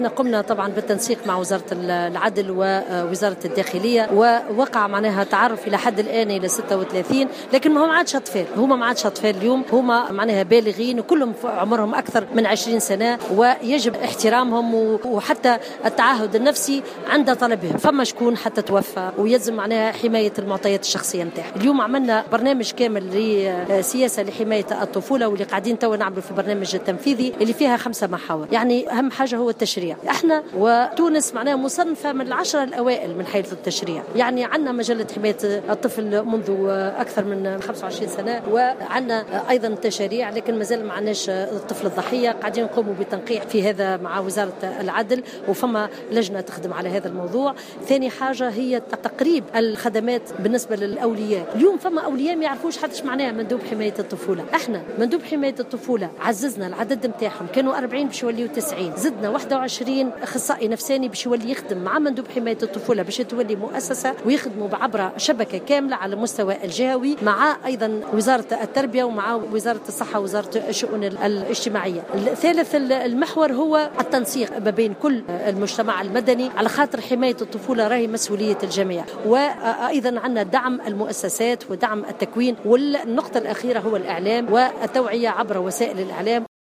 وأوضحت في تصريحات لـ "الجوهرة أف أم" أن تم التنسيق مع وزارتي العدل والداخلية بشأن الضحايا والتعرف على 36 ضحية، مؤكدة ضرورة احترام خصوصياتهم وحماية معطياتهم الشخصية بعد أن تجاوز جلهم سن العشرين وبينهم من توفى، بحسب تعبيرها.